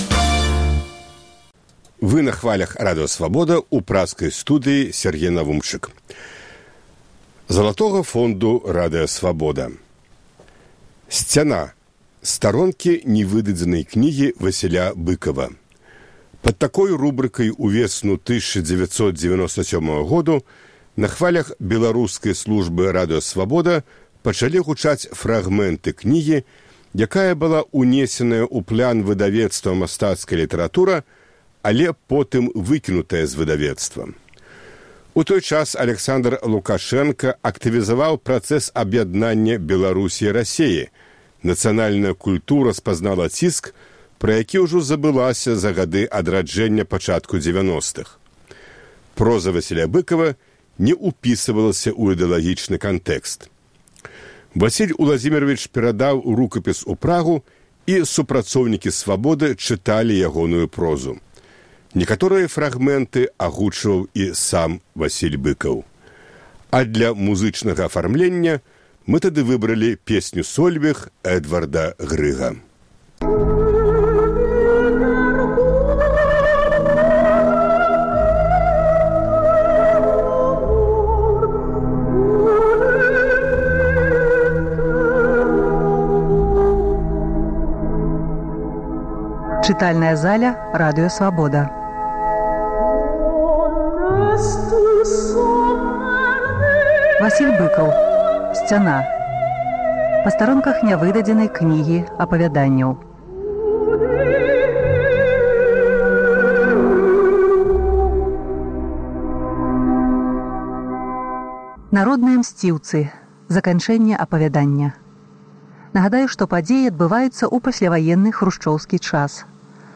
Сьцяна. Старонкі нявыдадзенай кнігі Васіля Быкава Пад такой рубрыкай увесну 1997 годзе на хвалях Беларускай службы Радыё Свабода пачалі гучаць фрагмэнты кнігі, якая была ўнесеная ў плян выдавецтва Мастацкая літаратура, але потым выкінутая зь яе.
Васіль Уладзімеравіч перадаў рукапіс ў Прагу, і супрацоўнікі Свабоды чыталі ягоную прозу.